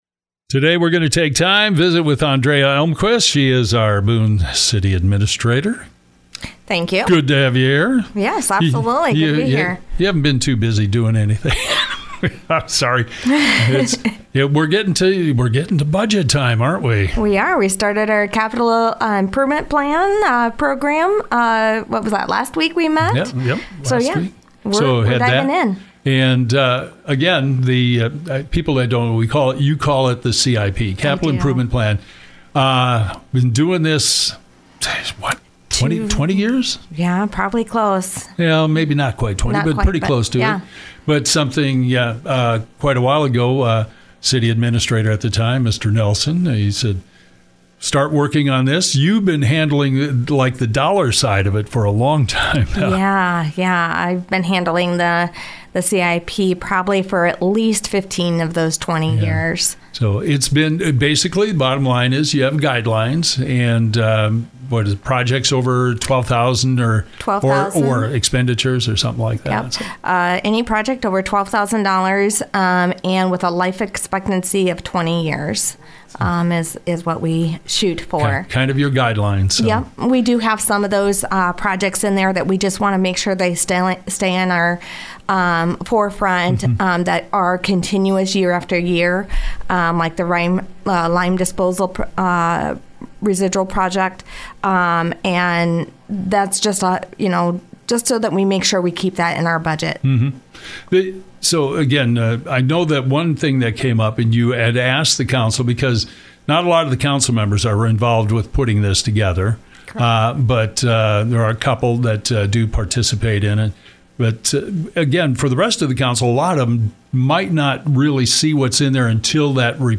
Ondrea Elmquist, Boone City Administrator, talks about budget preparation.